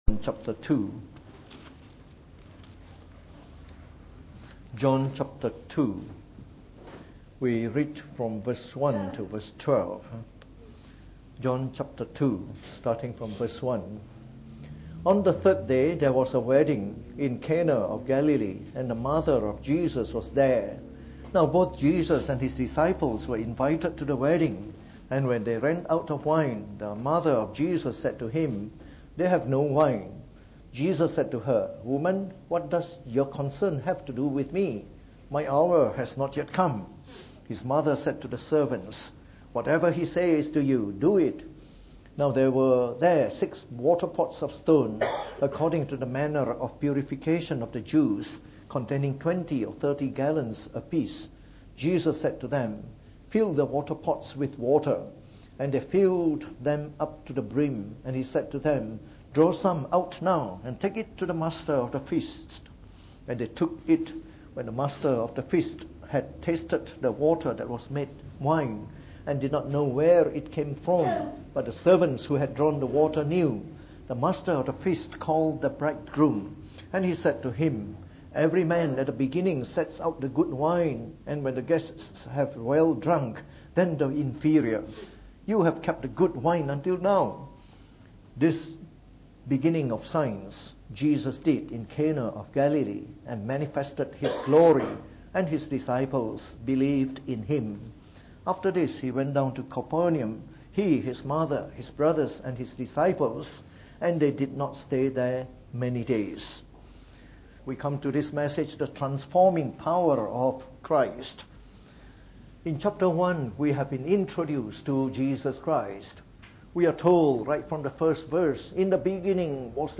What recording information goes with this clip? Preached on the 5th August 2018. From our series on the Gospel of John delivered in the Evening Service.